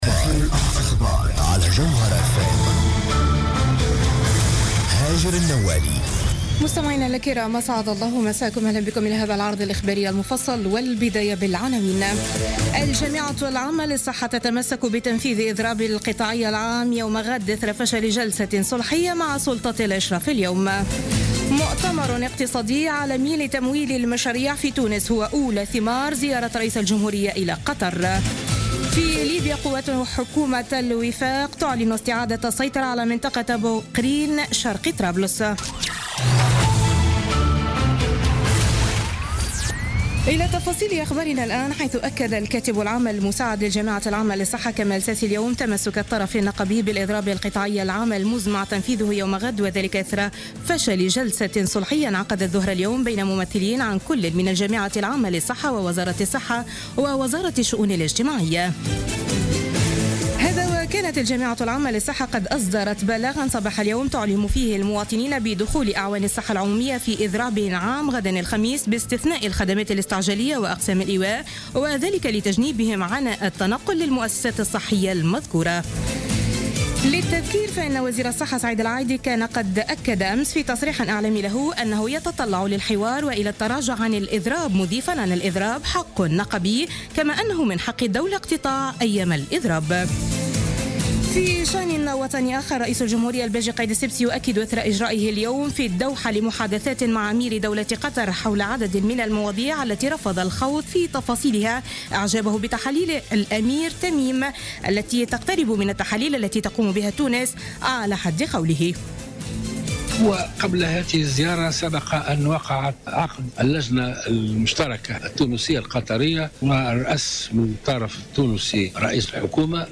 نشرة أخبار السابعة مساء ليوم الأربعاء 18 ماي 2016